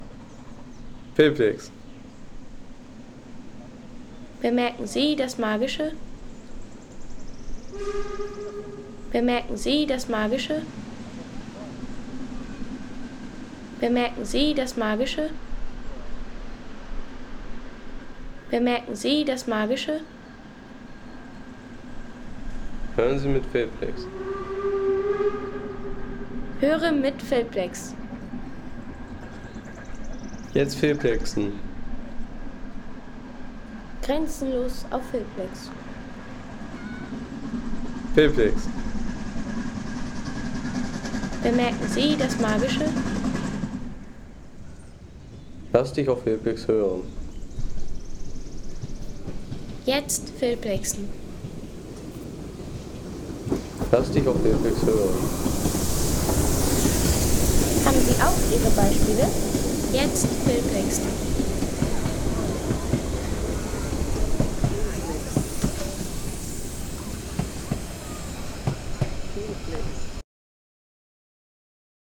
Dampflokomotive am Bahnhof